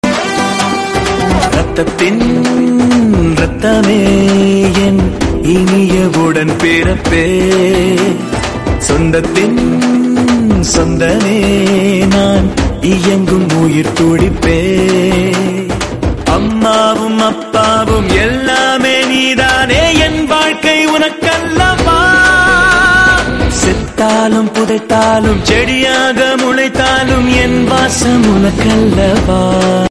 Ringtones Category: Bollywood ringtones